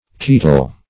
ketol.mp3